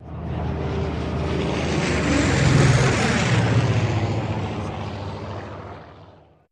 Airplane, WW2 Fg1D Corsair, Pass Overhead